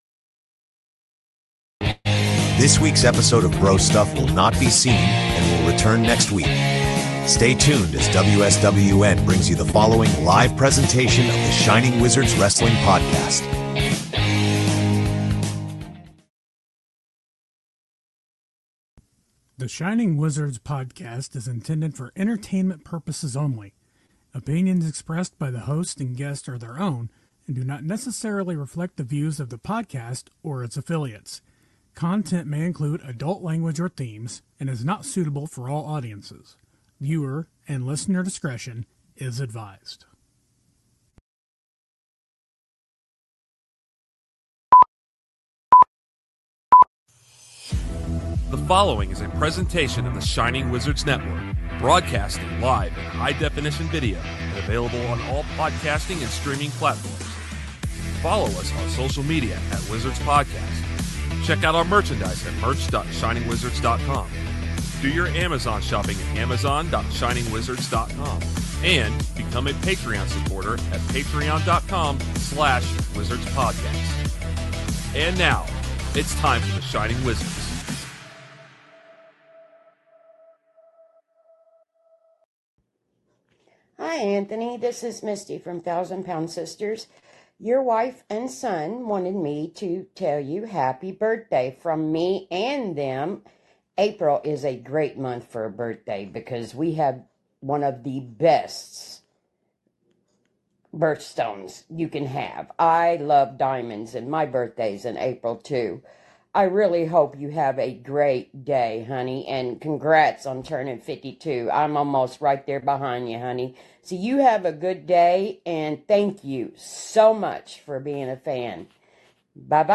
This is a must listen too interview and you should check out New Fear City, every show is free on Youtube.